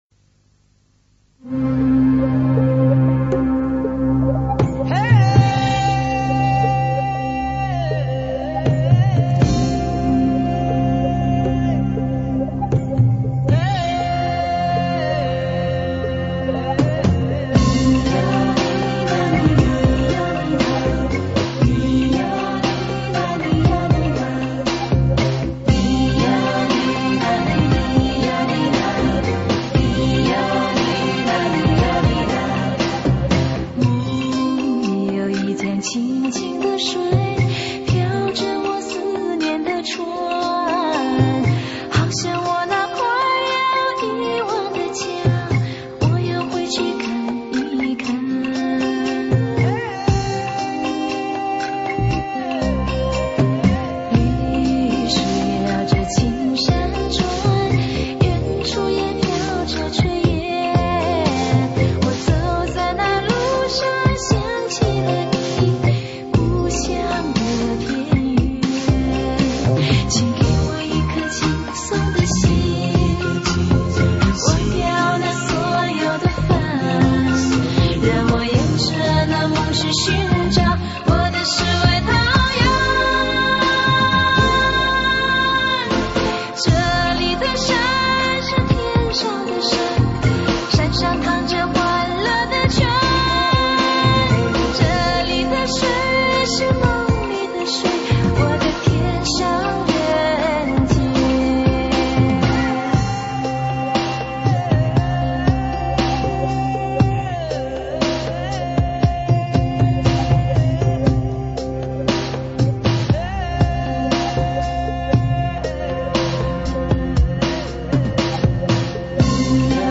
高音质。